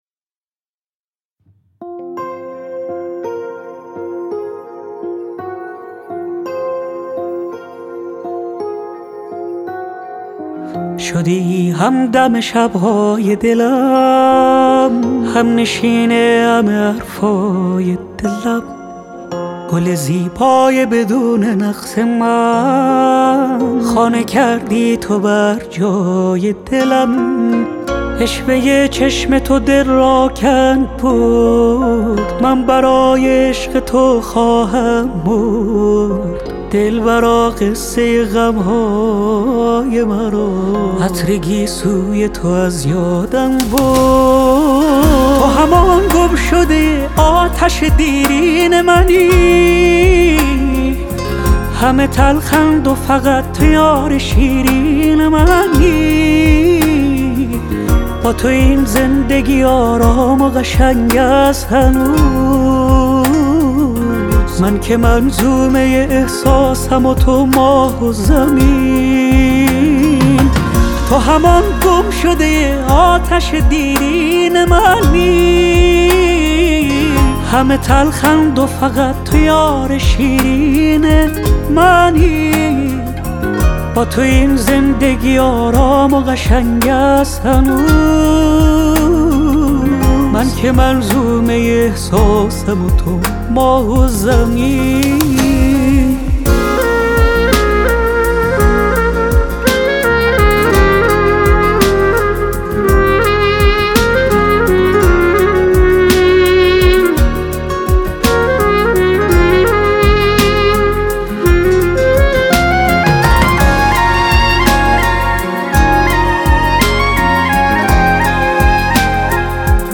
امروز اومدم با معرفی یه آهنگ احساسی جدید دیگه